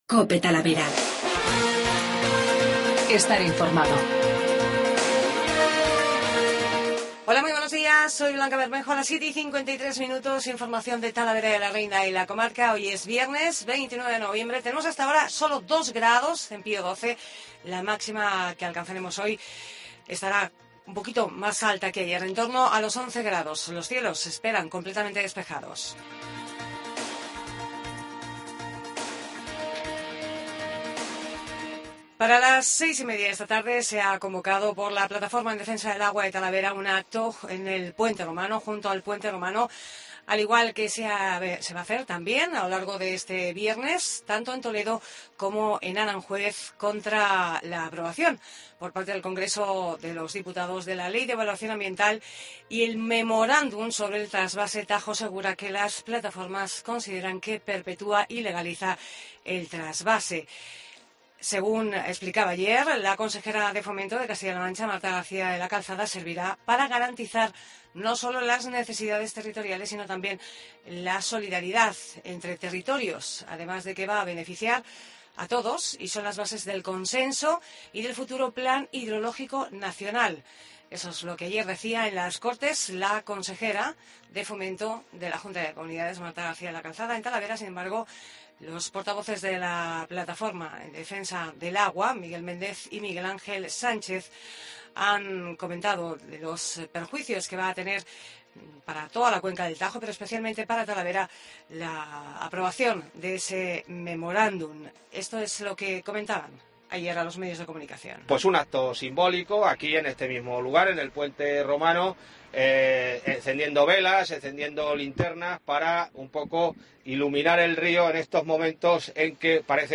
INFORMATIVO MATINAL 7.53